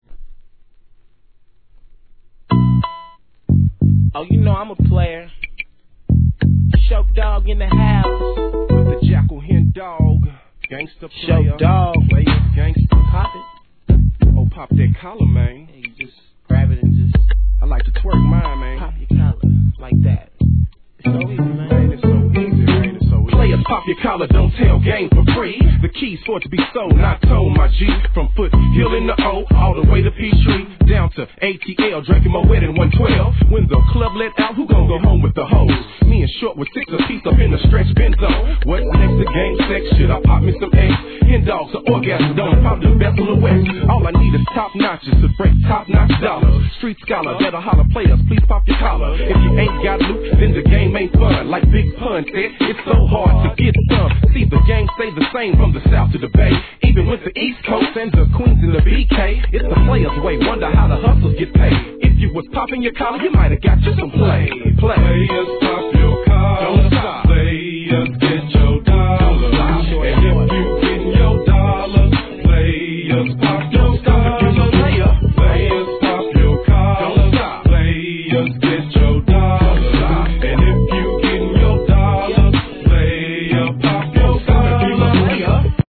G-RAP/WEST COAST/SOUTH
メロ〜好FUNKが揃いに揃ったEP!!